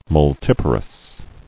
[mul·tip·a·rous]